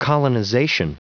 Prononciation du mot colonization en anglais (fichier audio)
Prononciation du mot : colonization